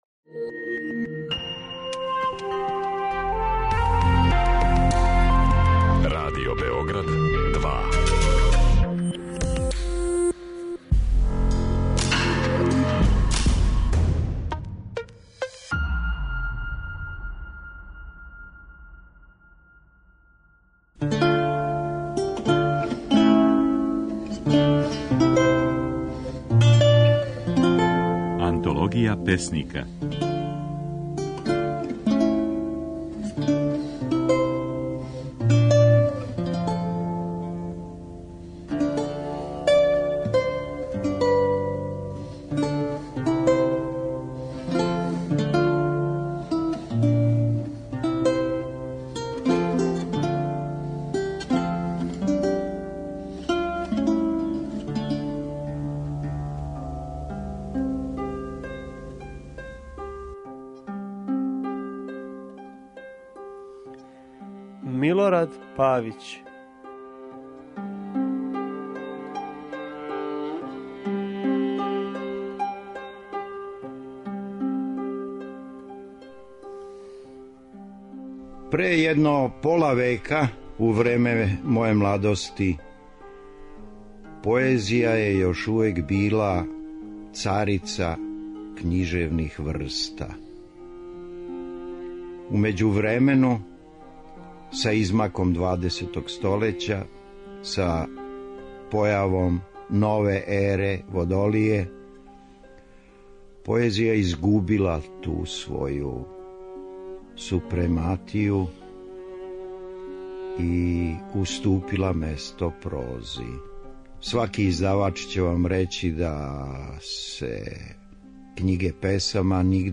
Kако је своје стихове говорио Милорад Павић
Од 31. јула дo 4. августа, можете слушати како је своје стихове говорио романсијер, приповедач, драмски писац, али и песник - Милорад Павић (1929-2009).